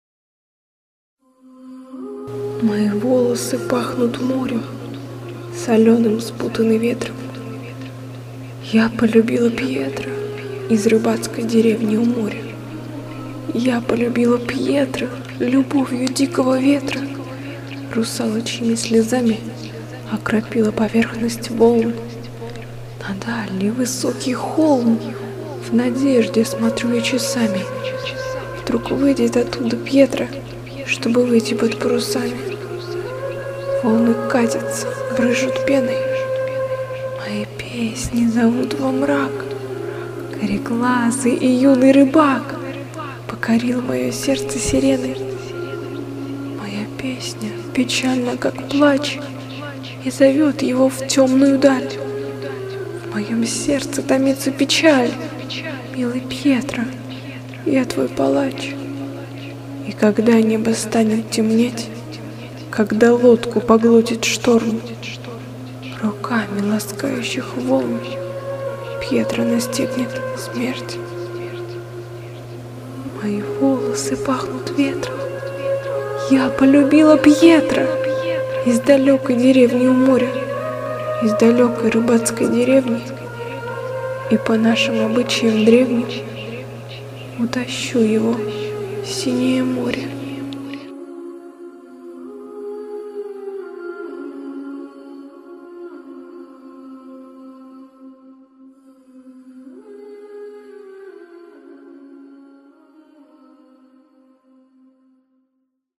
У Вас приємний голос, а в поєднанні з драматичним виконанням все просто чудово) відчув себе наче всередині виру подій 05